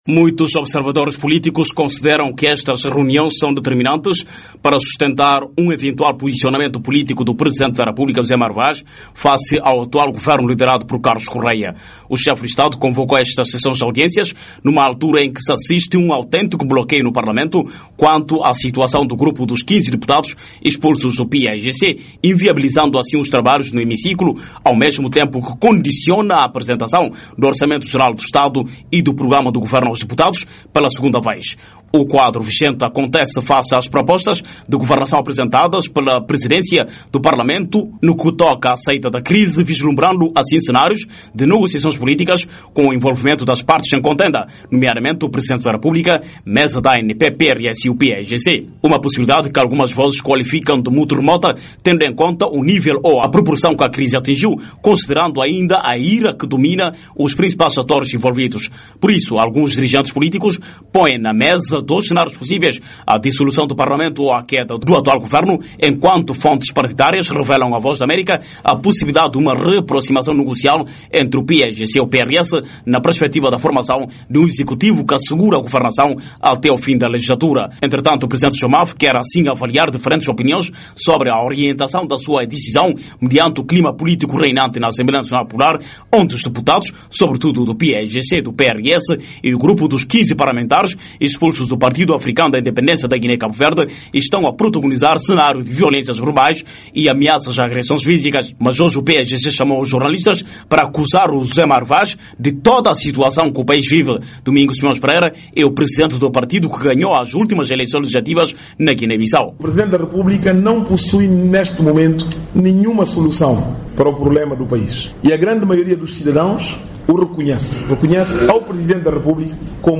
Em conferência de imprensa nesta segunda-feira, 9, Simões Pereira defendeu a realização de eleições gerais.